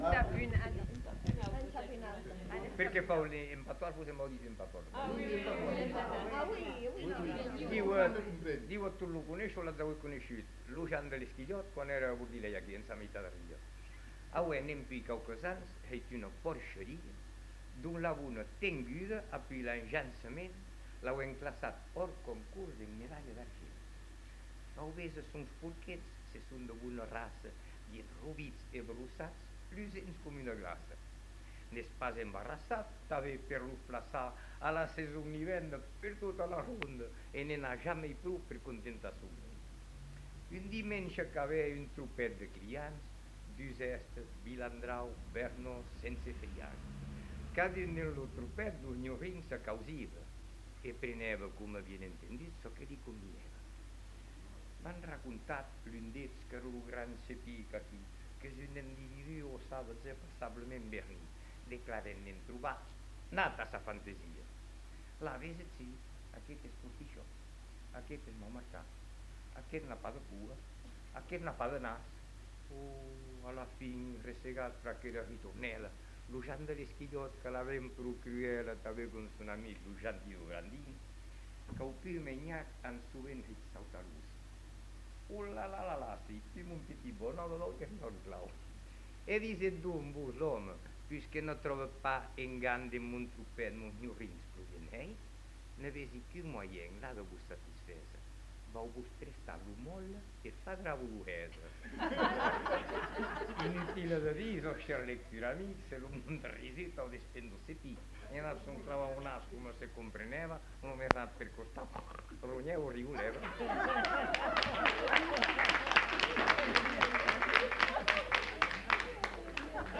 Lieu : Bazas
Genre : conte-légende-récit
Effectif : 1
Type de voix : voix d'homme
Production du son : récité
Classification : monologue